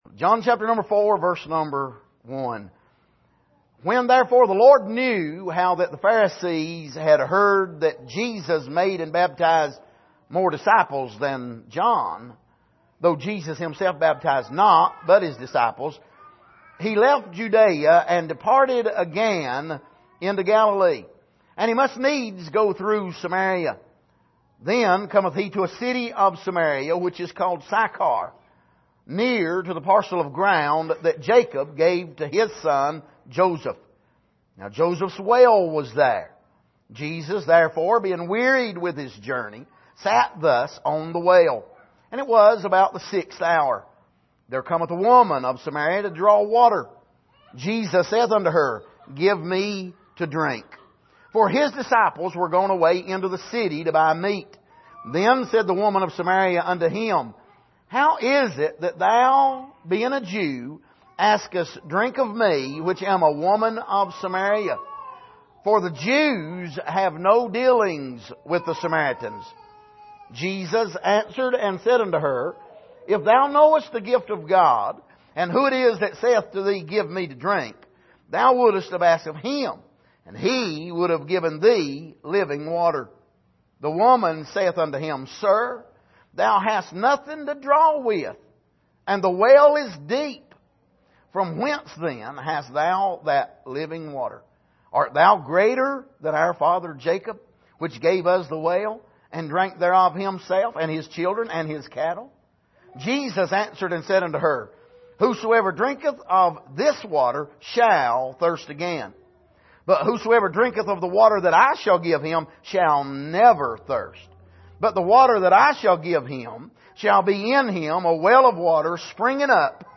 Passage: John 4:1-14 Service: Sunday Morning